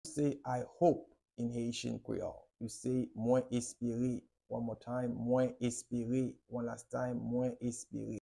How to say I hope in Haitian Creole - Mwen espere pronunciation by a native Haitian Teacher
“Mwen espere” Pronunciation in Haitian Creole by a native Haitian can be heard in the audio here or in the video below:
How-to-say-I-hope-in-Haitian-Creole-Mwen-espere-pronunciation-by-a-native-Haitian-Teacher.mp3